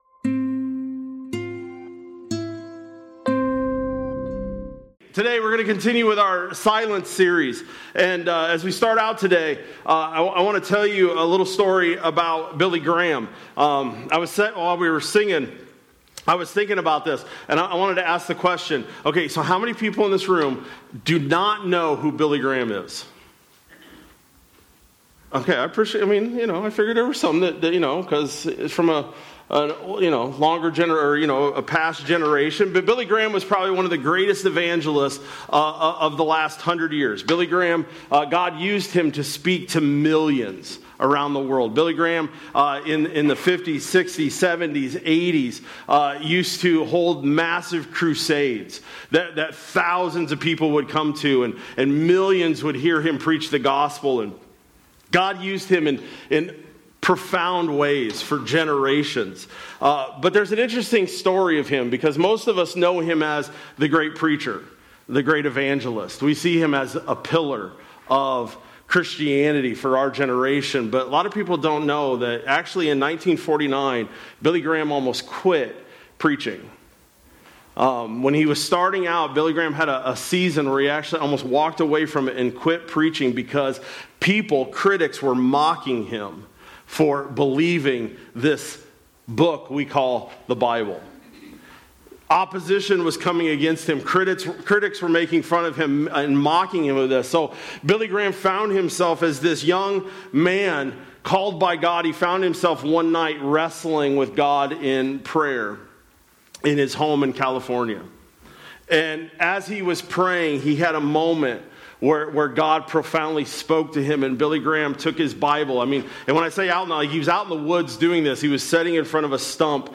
Nov-9-25-Sermon-Audio.mp3